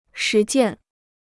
实践 (shí jiàn) Free Chinese Dictionary